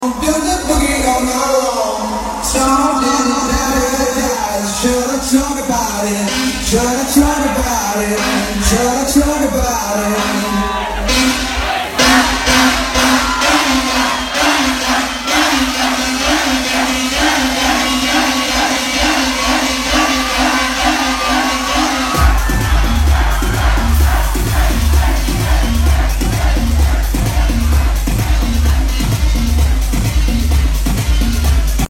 guaracha